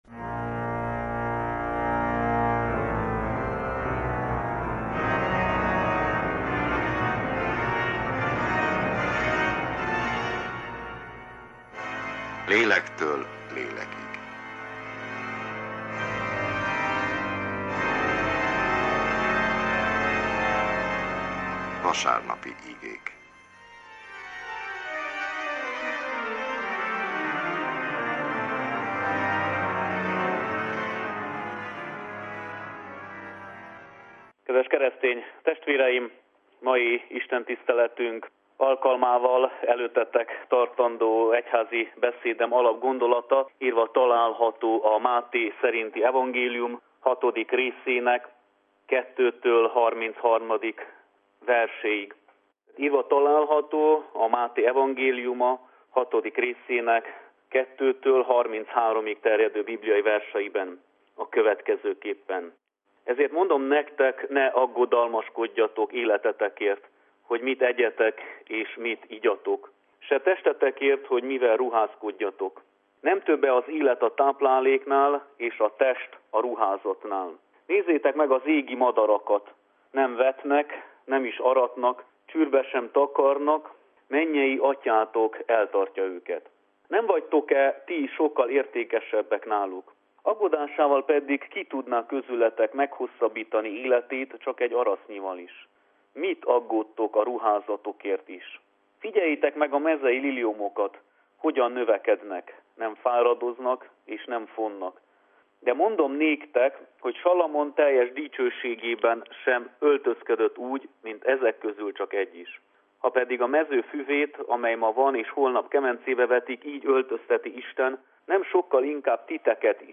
Unitárius igehirdetés, augusztus 28.
Egyházi műsor